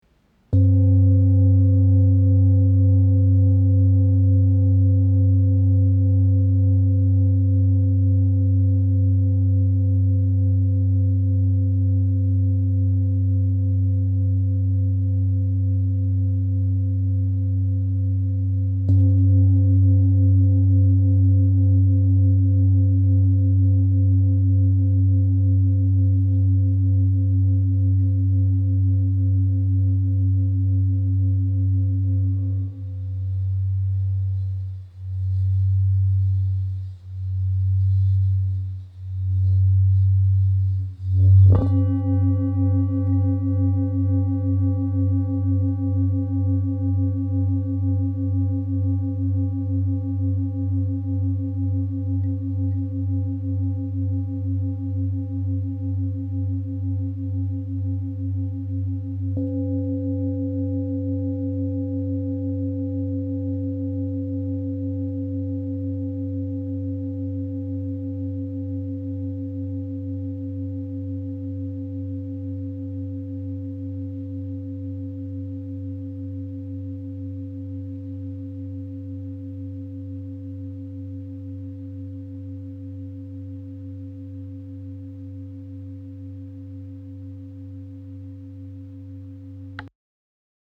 Bol tibétain Ulta Bathi • Fa# 47Hz
Le bol Ulta Bathi est réputé pour sa résonance grave et vibrante. Ses vibrations basses et prolongées font de lui un allié idéal pour ceux qui recherchent un instrument puissant et efficace pour le travail énergétique et l’harmonisation intérieure.
Note : Fa# 47 Hz
Diamètre : 27,8 cm